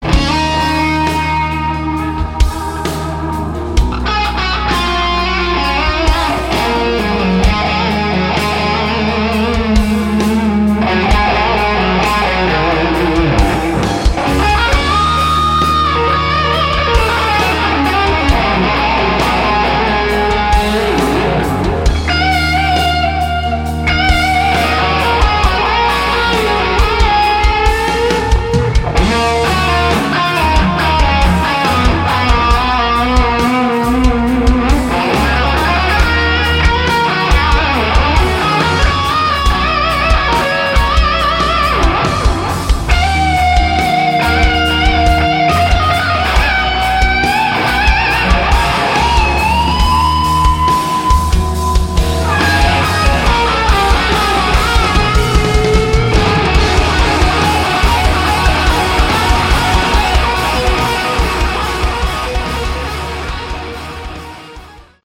Category: Sleazy Hard rock
guitar
vocals
drums
bass